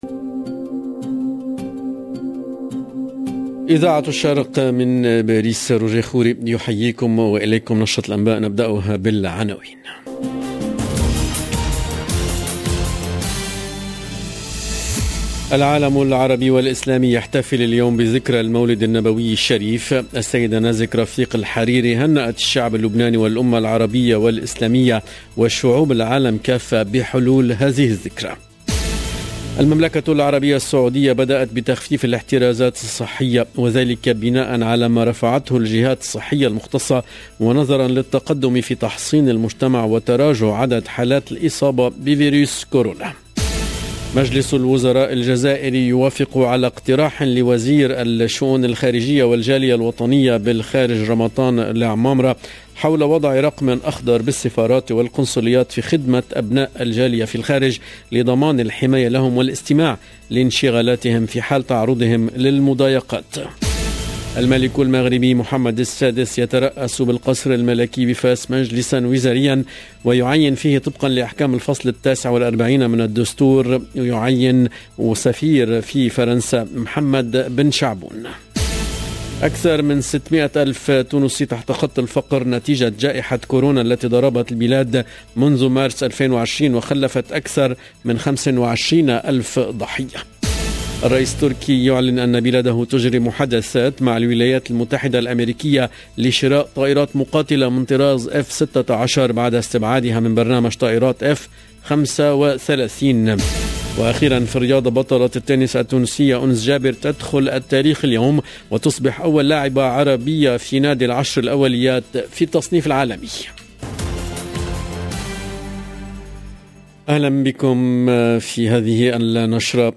Le journal de midi 30 en langue arabe du 18/10/21